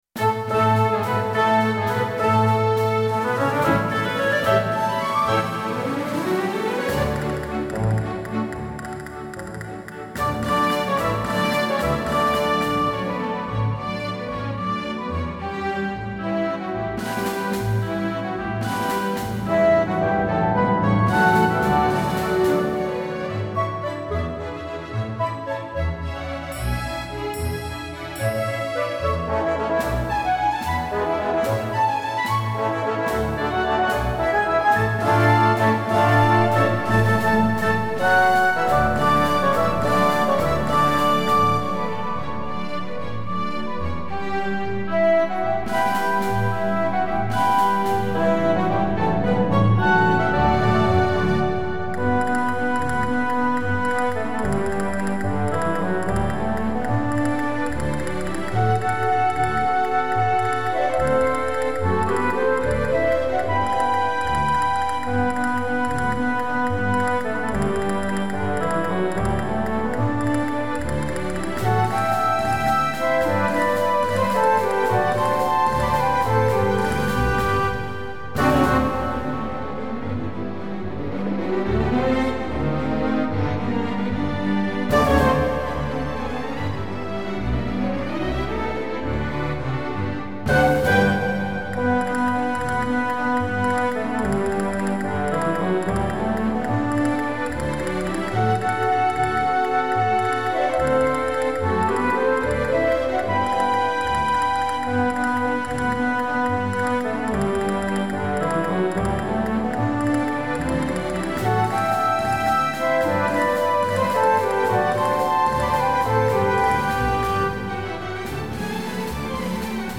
ピアノ譜から管弦楽への編曲です。
スペインの民族的な旋律が取り入れられた 南国的な明るさに満ちたワルツです。
音源は、楽譜のサンプルとして添付いたします。